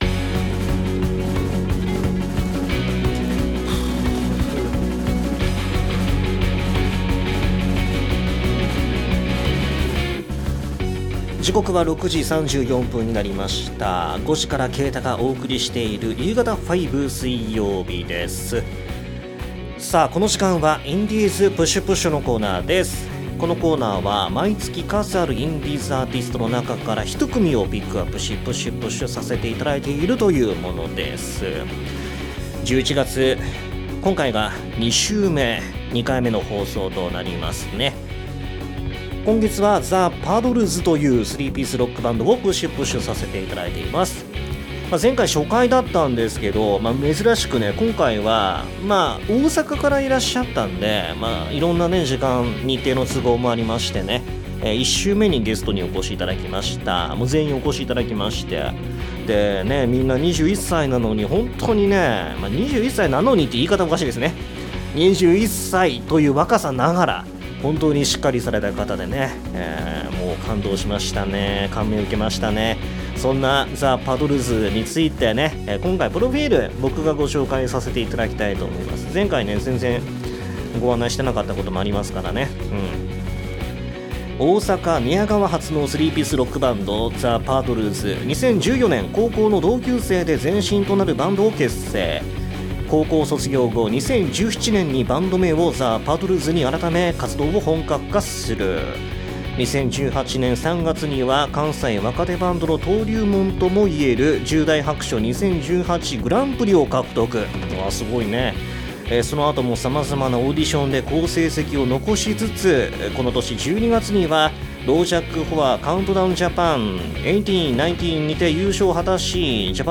<同録音源> ※権利上の都合により楽曲はカットしています。